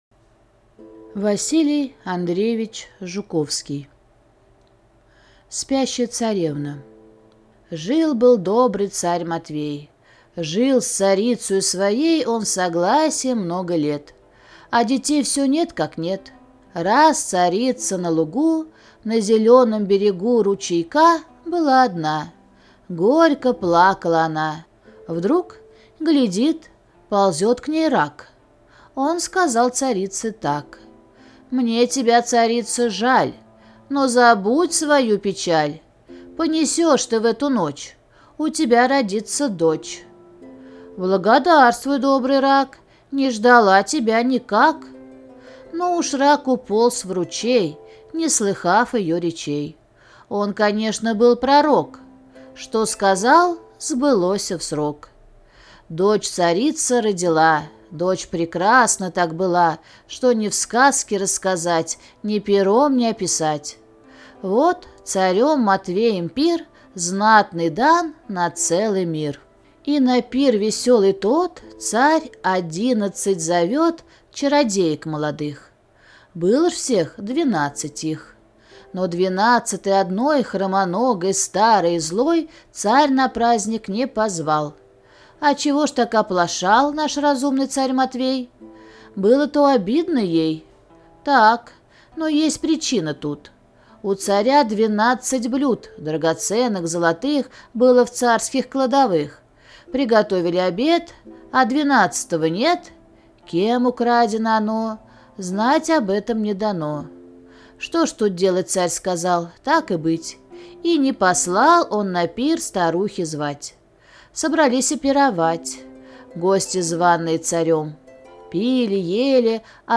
Спящая царевна - аудиосказка Жуковского - слушать онлайн